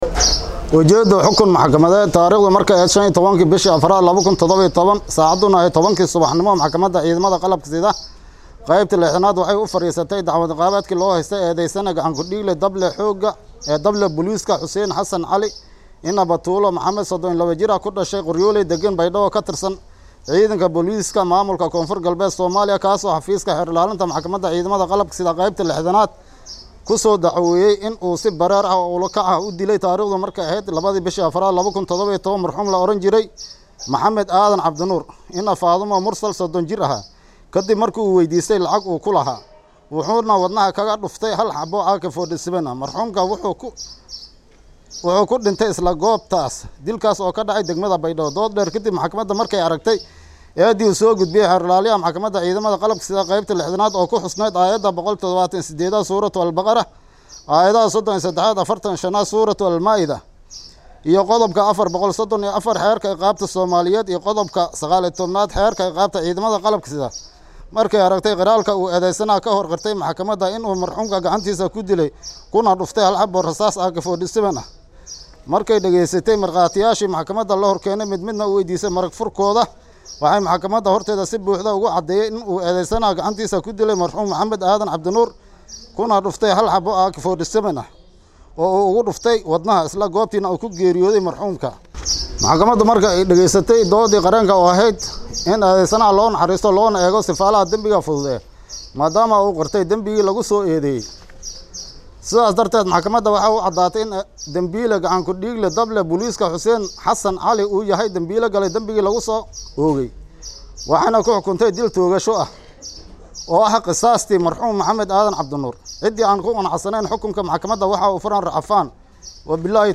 Guddoomiyaha Maxakamadda ciidamada Qalabka sida Soomaaliya ee Qeybta 60aad Sheekh Aadan Abuukar Maxamed ayaa ku dhawaaqay xukunka Maxkamadda.
Codka-Gudoomiyaha-Maxakamada-Ciidamada-Qalabka-sida-ee-Soomaaliya-ee-Qeybta-60-aad-Sheekh-Aadan-Abuukar-Maxamed..mp3